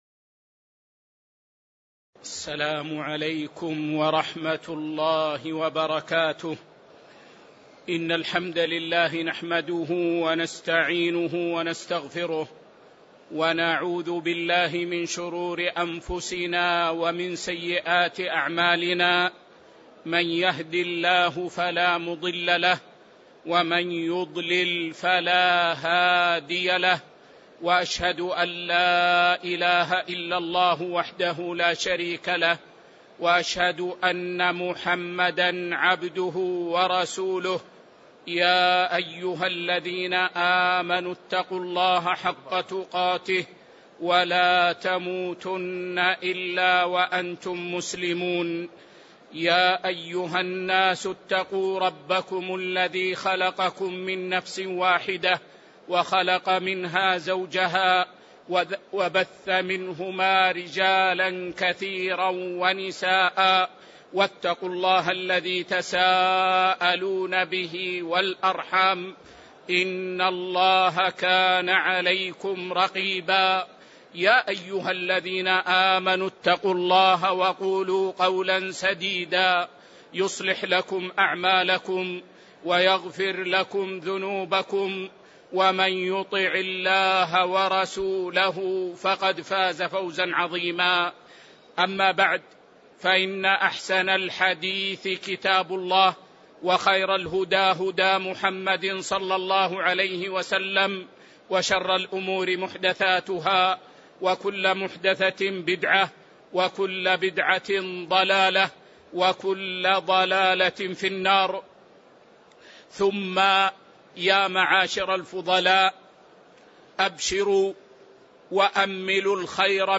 تاريخ النشر ١٩ رمضان ١٤٤٣ هـ المكان: المسجد النبوي الشيخ